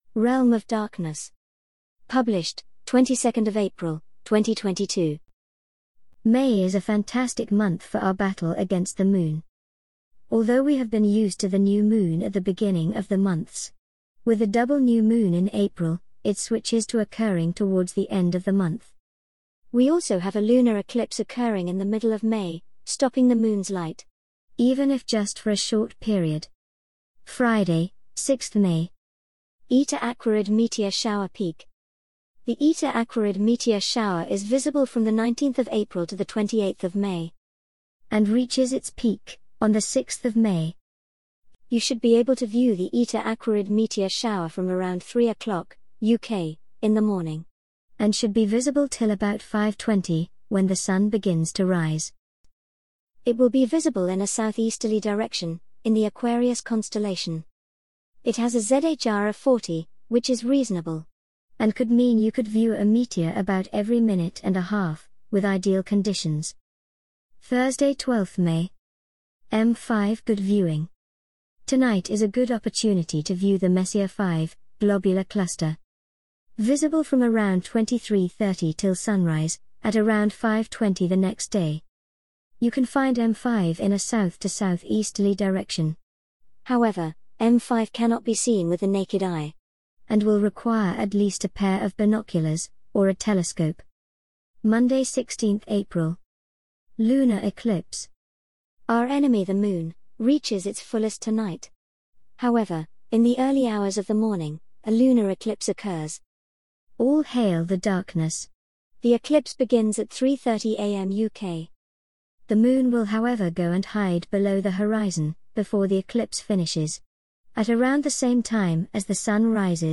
An audio reading of the Realm of Darkness May 2022 Article